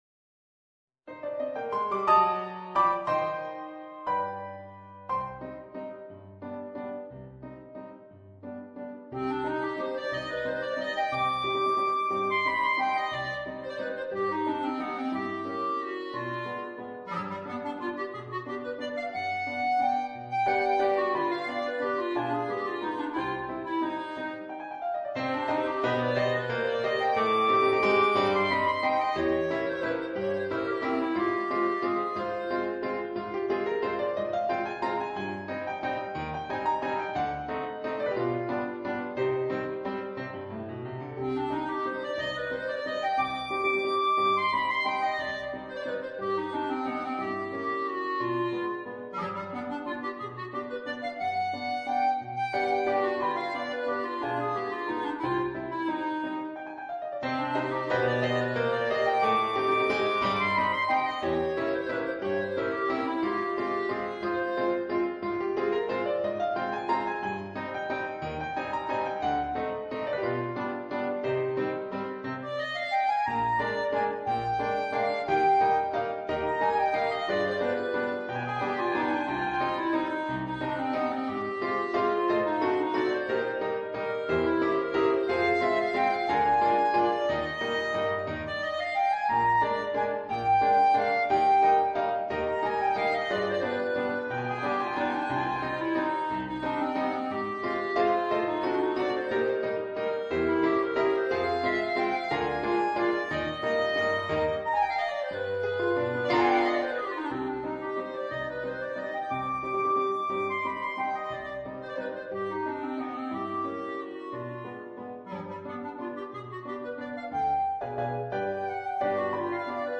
per clarinetto e pianoforte